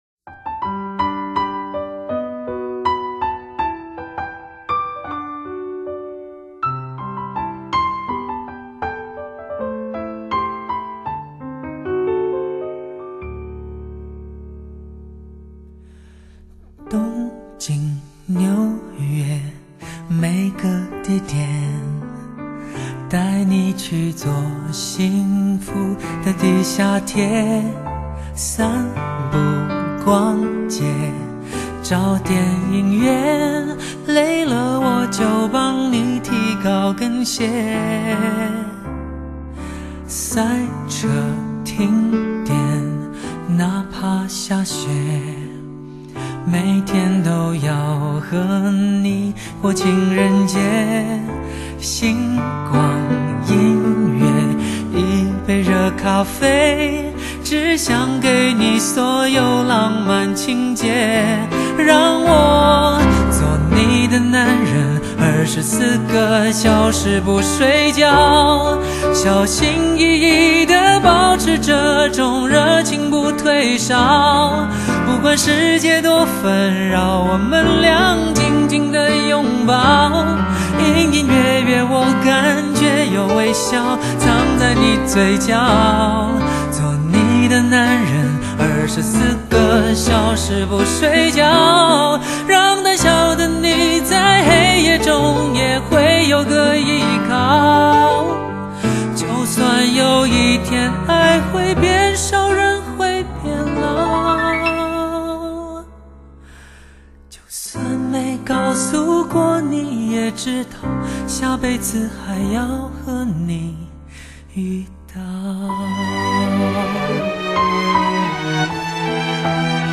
2006最深情浪漫的求婚必备曲
由简单深情的钢琴搭配弦乐四重奏简约伴奏
就像在情人耳边缓缓地浪漫倾诉